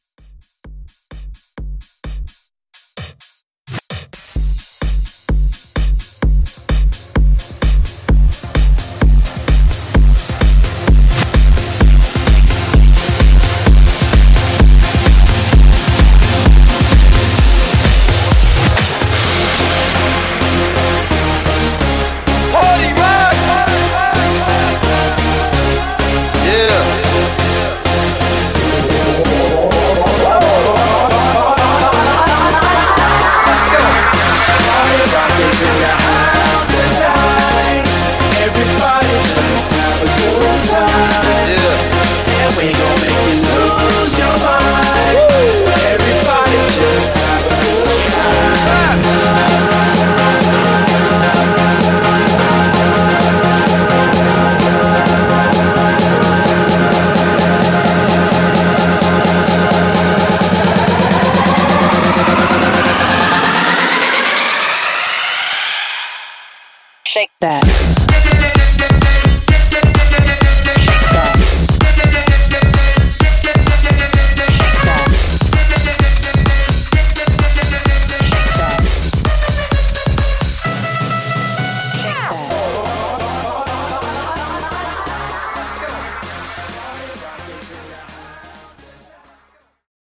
90's , R & B , RE-DRUM 90 Clean